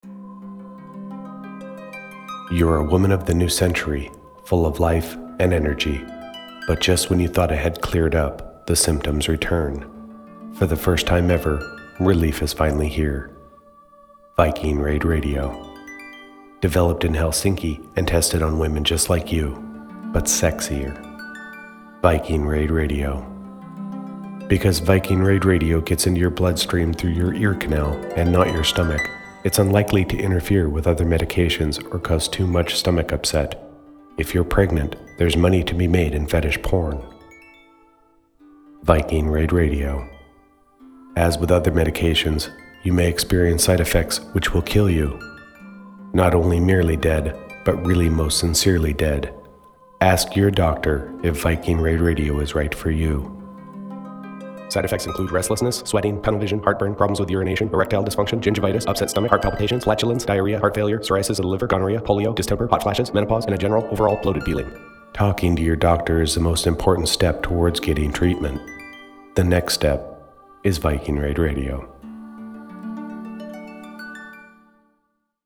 I did three promos based on pharmaceutical ads.